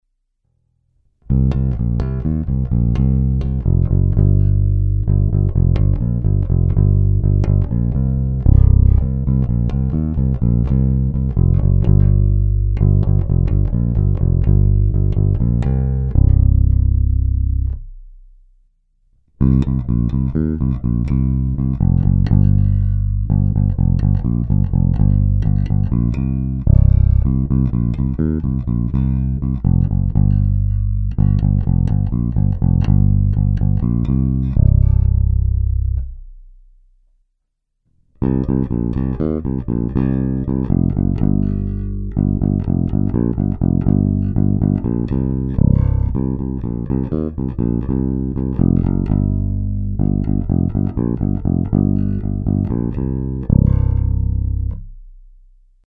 Ukázky jsou nahrány rovnou do zvukové karty a jen normalizovány.